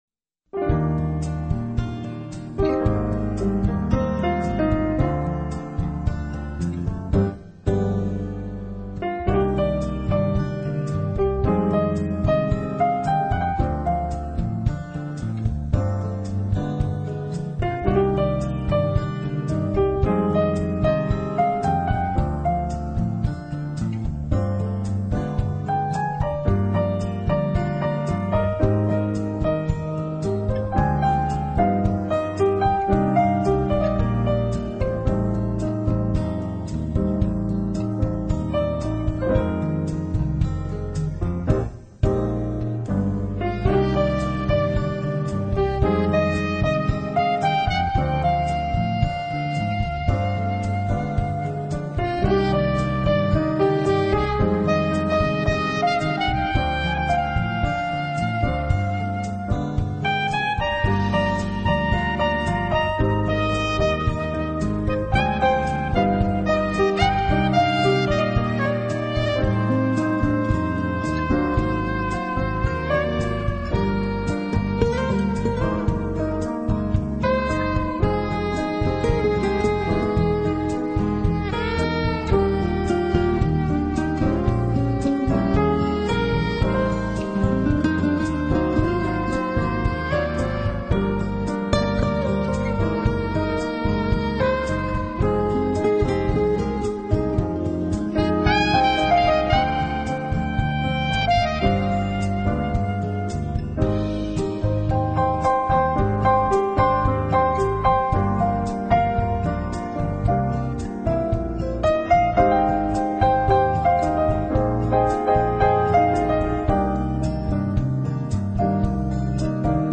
节奏柔缓急促有间，雍容名贵。